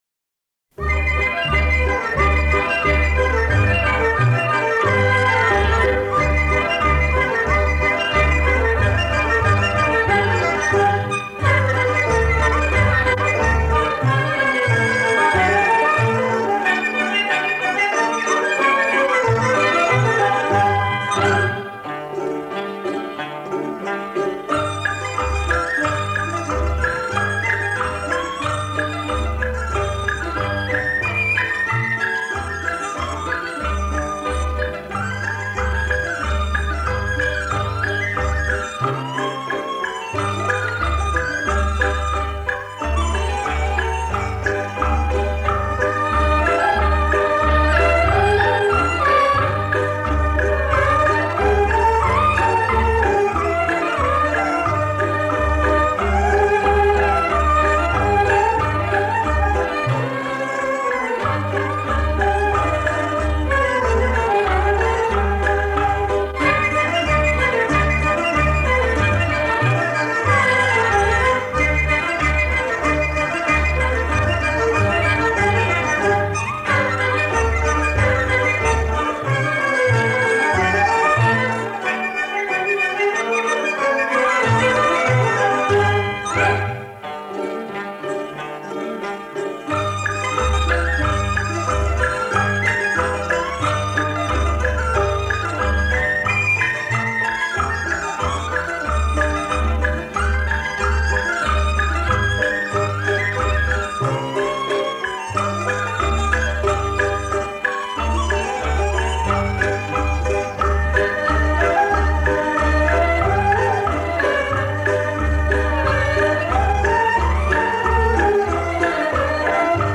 轻音乐
经典的历史录音版本